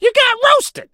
phoenix_crow_kill_vo_09.ogg